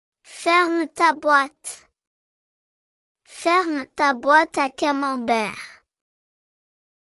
\Fairm-TAH-Bwot\
\Fairm-TAH-Bwot-Duh-Cam-Mom-Bear\